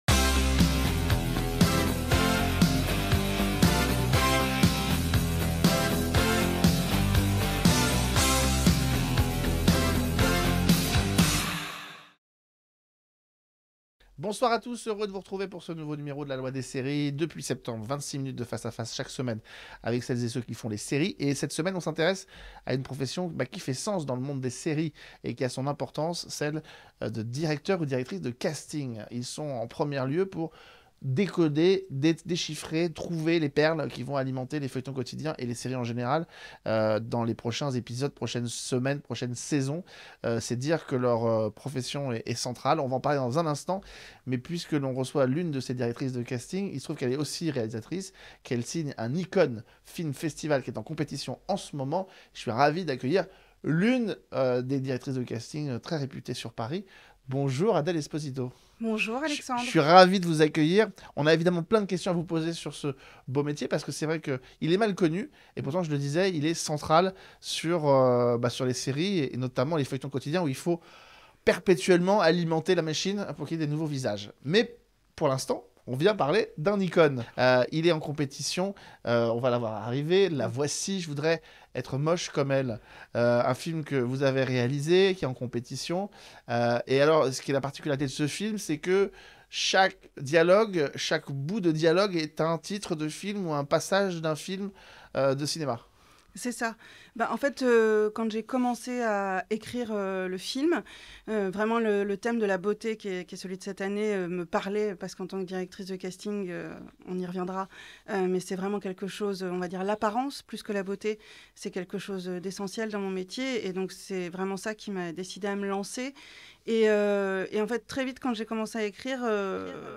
L’invitée